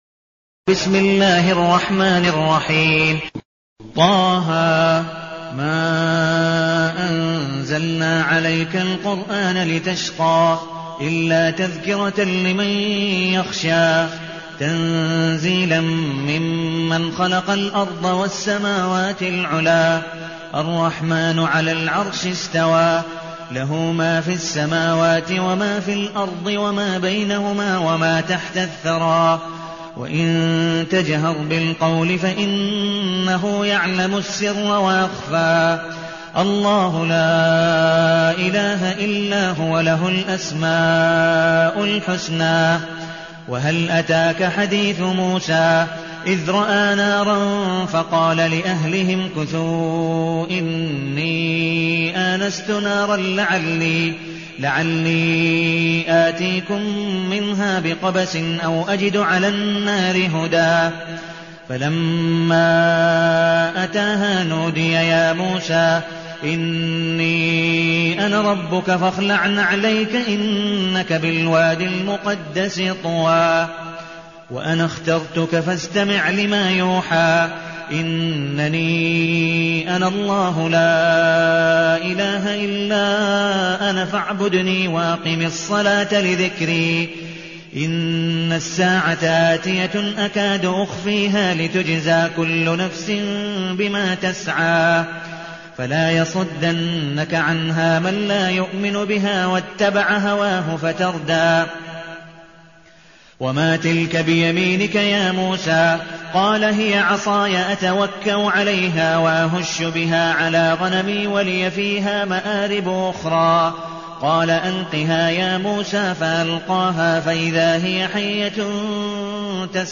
المكان: المسجد النبوي الشيخ: عبدالودود بن مقبول حنيف عبدالودود بن مقبول حنيف طه